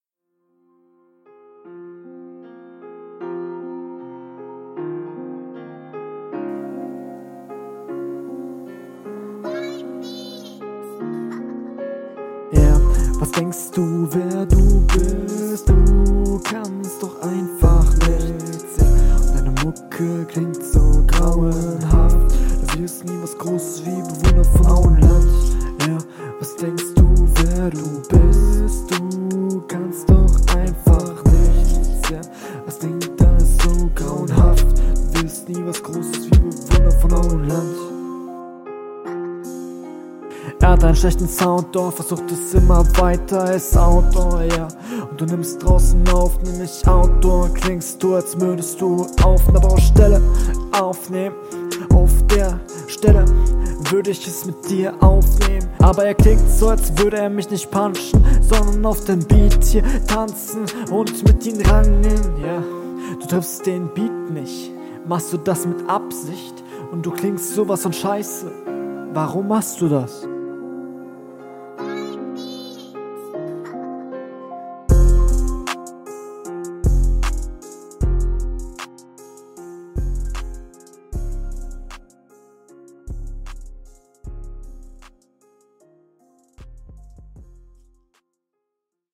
Der erste Gesangspart hat mir nicht wirklich gefallen.